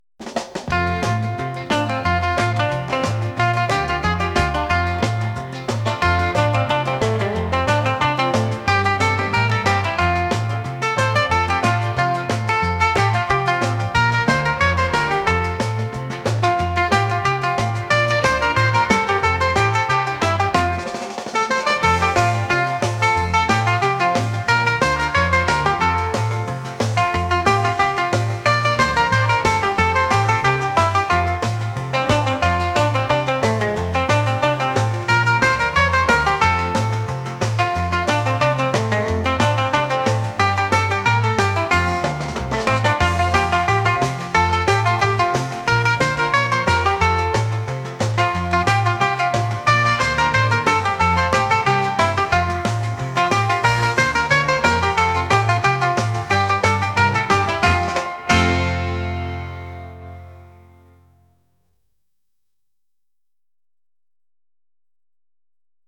classical | pop | holiday